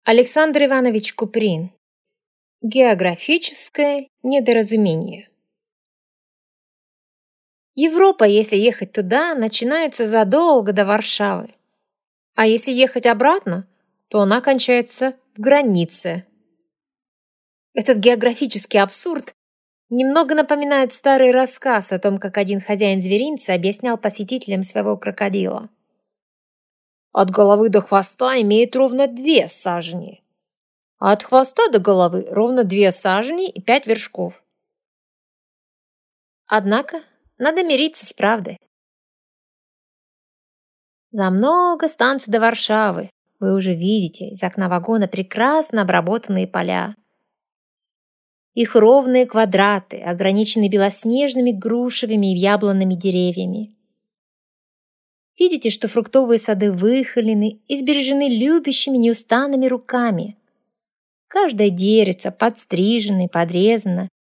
Аудиокнига Географическое недоразумение | Библиотека аудиокниг
Прослушать и бесплатно скачать фрагмент аудиокниги